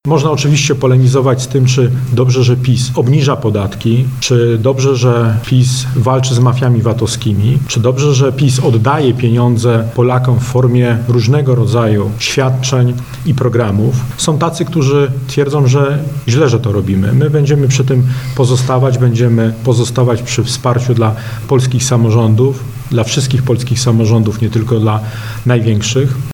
W radomskiej siedzibie Prawa i Sprawiedliwości podsumowano działania partii w dobie kryzysu, które mają na celu wsparcie polskich rodzin
Jak podkreśla poseł Andrzej Kosztowniak Prawo i Sprawiedliwość jest taka partią, która realizuje to, co obiecuje: